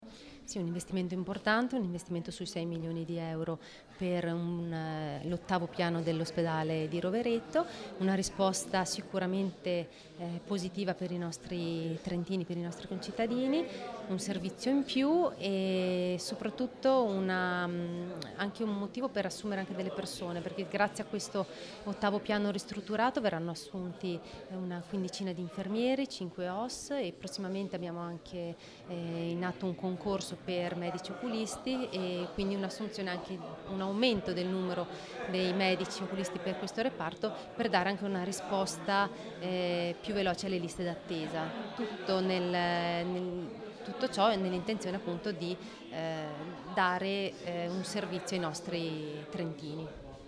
assessore_Segnana_inaugurazione_8_paino_rovereto.mp3